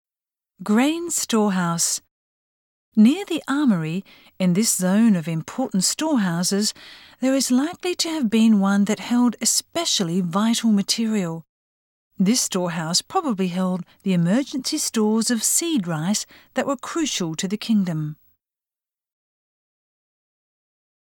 This storehouse probably held the emergency stores of seed rice that were crucial to the kingdom. Voice guide PREV NEXT Keitai-Guide TOP (C)YOSHINOGARIHISTORICAL PARK